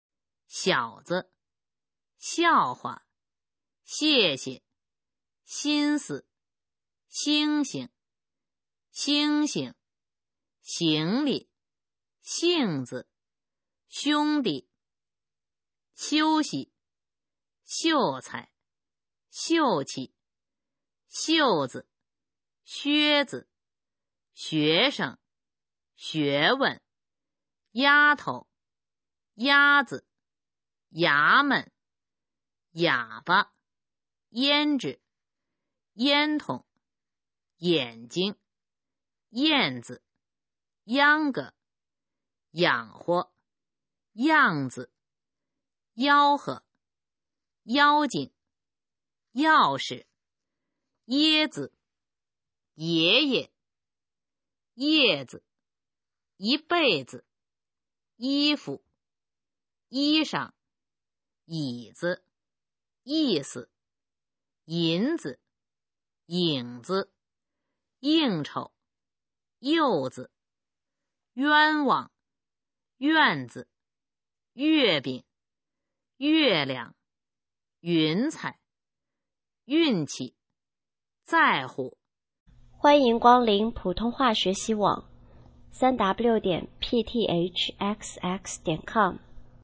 普通话水平测试用必读轻声词语表示范读音第451-500条